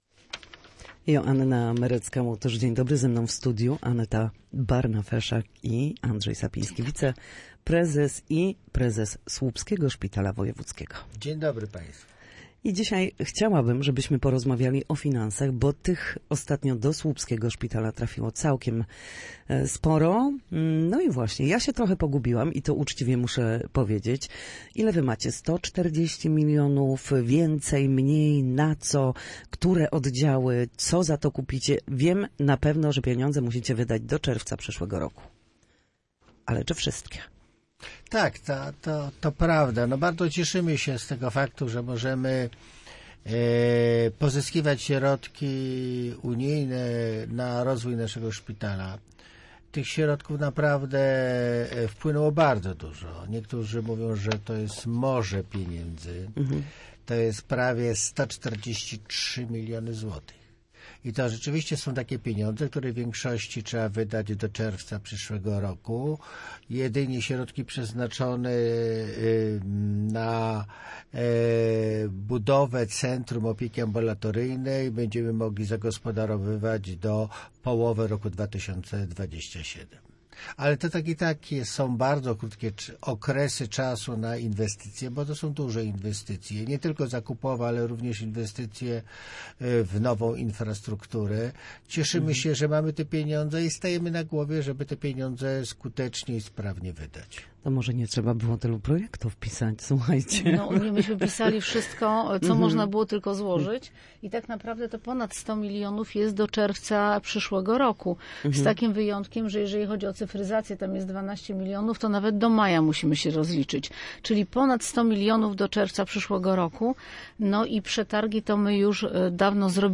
Prawie 143 miliony złotych na rozwój, zakup sprzętu, cyfryzację i modernizację pozyskał Wojewódzki Szpital Specjalistyczny w Słupsku z Krajowego Planu Odbudowy i Narodowej Strategii Onkologicznej. Gośćmi w Studiu Słupsk byli